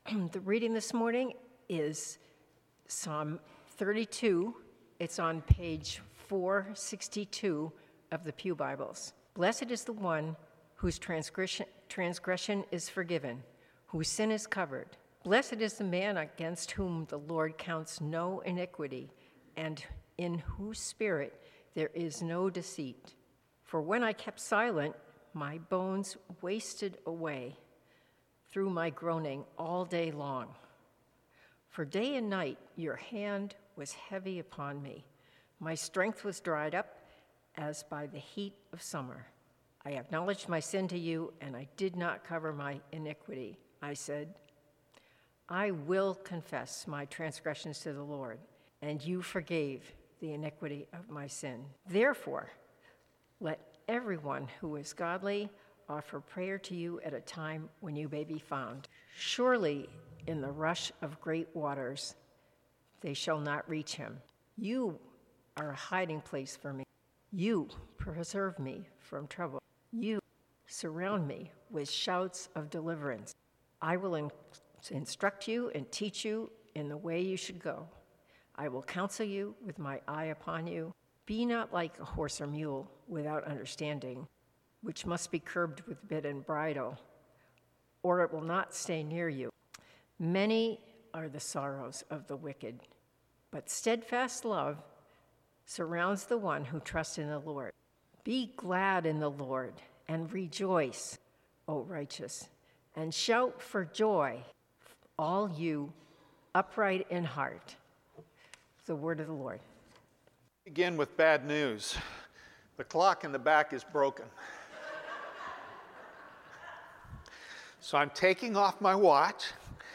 Passage: Psalm 32 Sermon